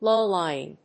アクセントlów‐lýing
音節low･ly･ing発音記号・読み方lòʊláɪɪŋ